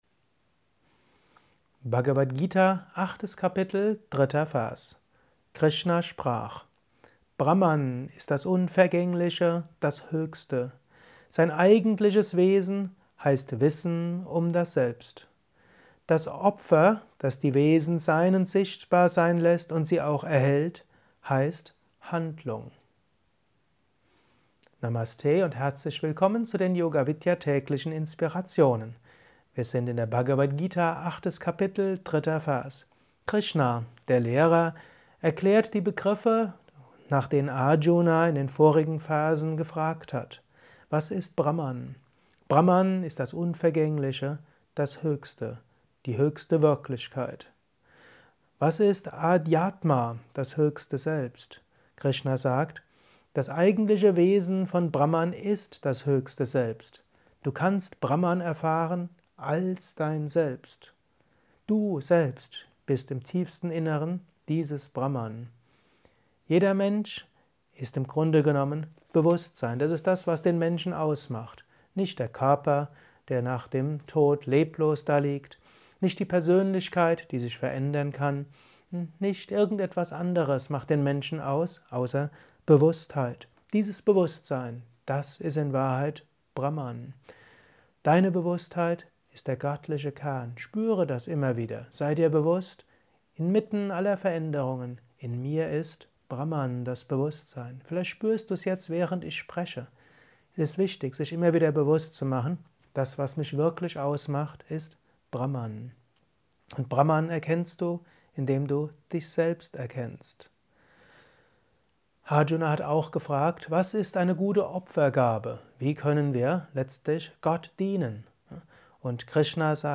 Kurzvortrag über die Bhagavad Gita